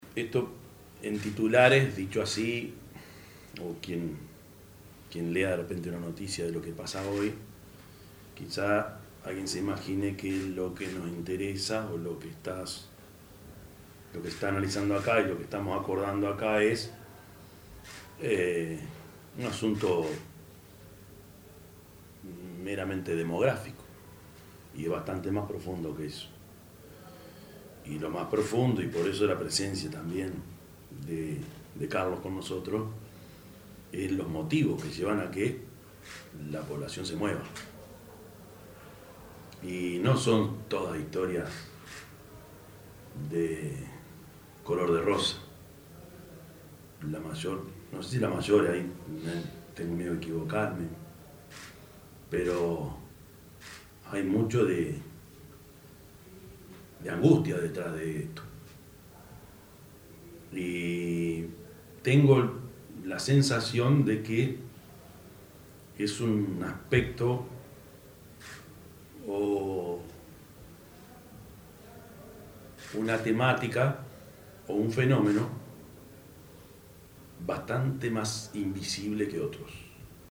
yamandu_orsi_intendente_de_canelones_0.mp3